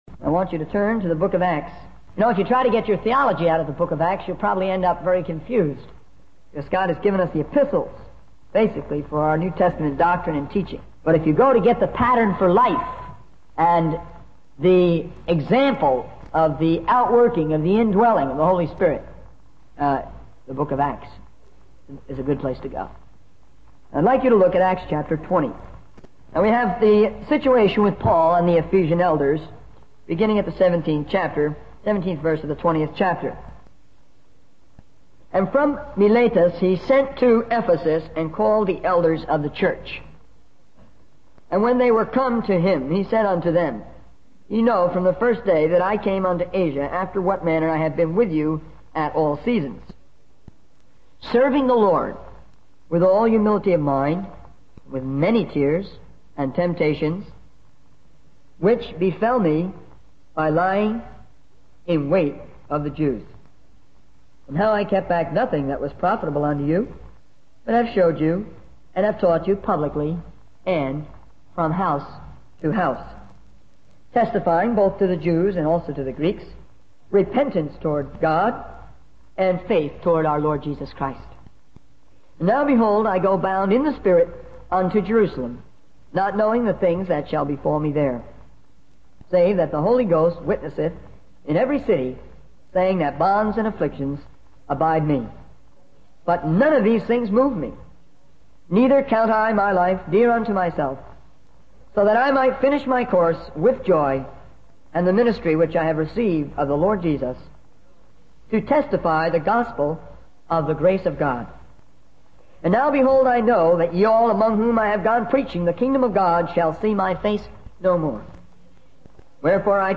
In this sermon, the speaker emphasizes the importance of young college men receiving a specific message. He highlights the need for discipline, warfare, self-denial, and unity among believers.